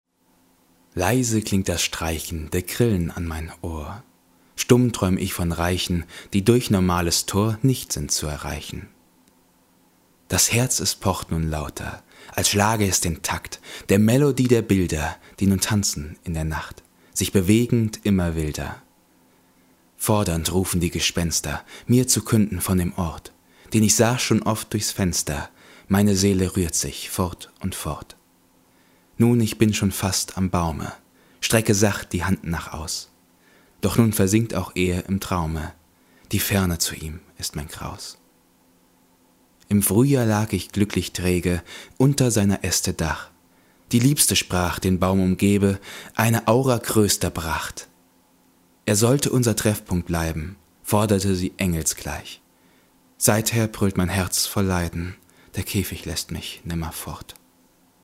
Kategorie: Gedichte, Gesprochenes